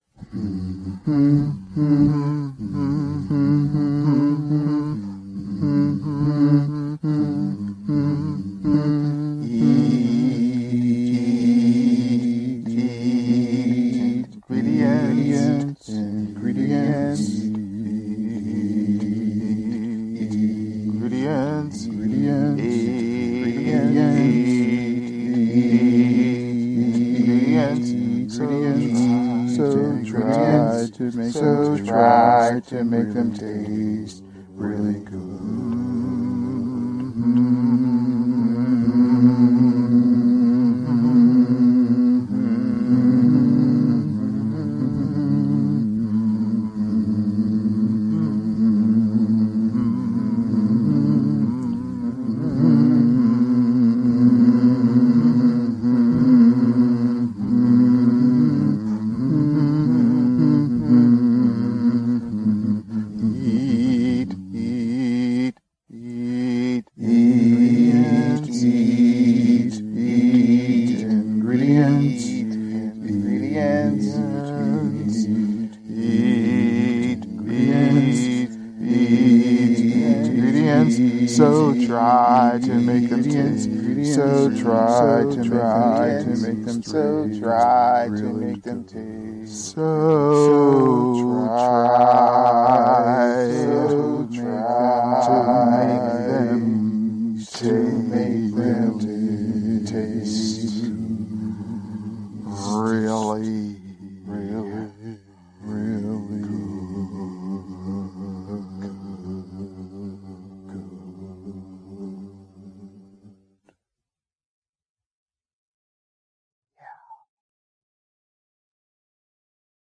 The theme song, sung by chant.